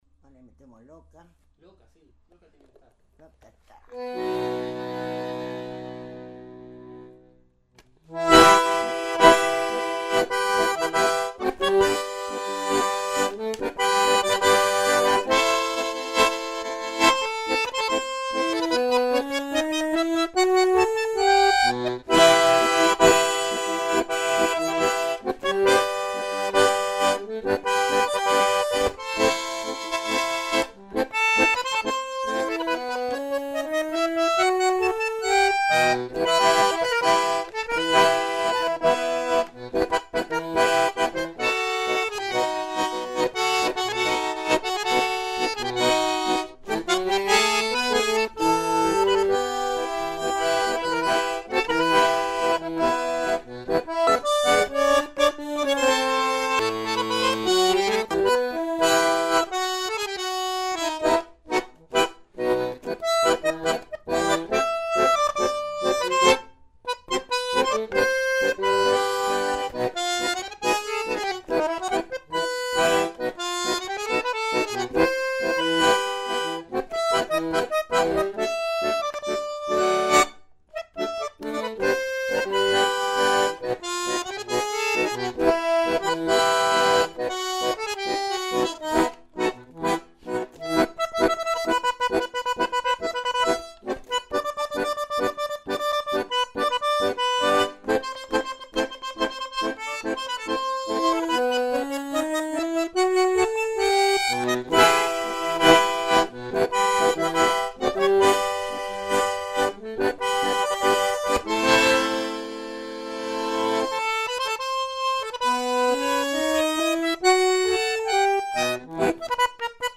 bandoneón
Su forma de tocar era estridente, con mucha potencia y con mucho ritmo.